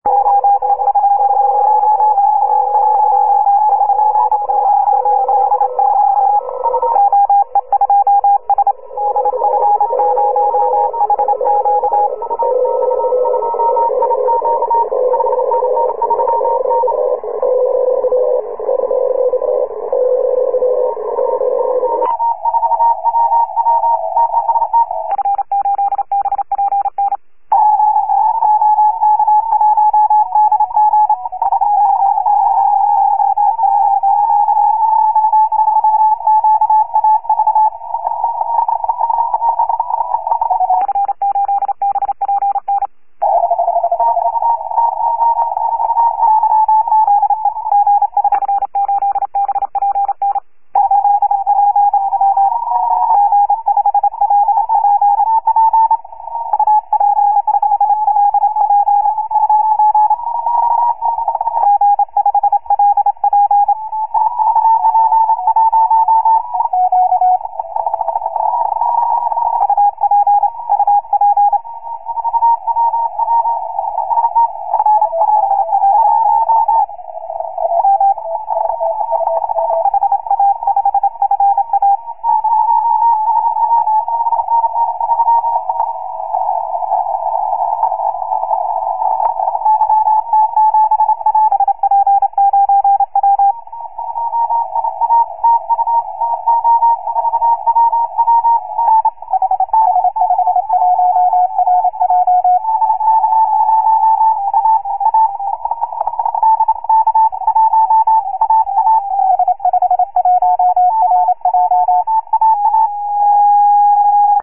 7 MHz CW pile-up, 29/11/03